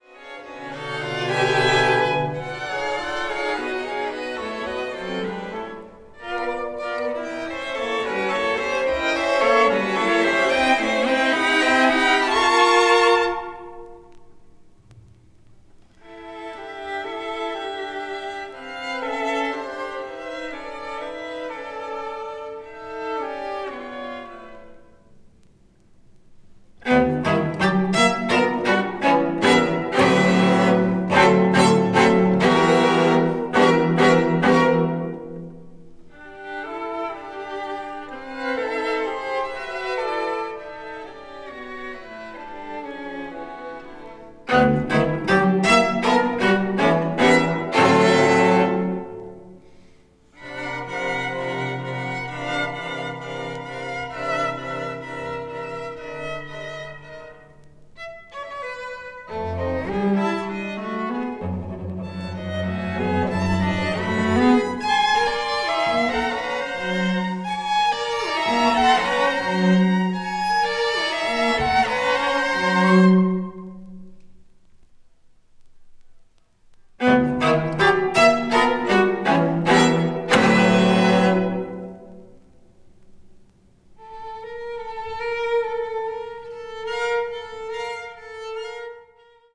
media : EX+/EX-(わずかにチリノイズが入る箇所あり,B前半:一部軽いチリノイズが入る箇所あり)
20th century   avant-garde   chamber music   contemporary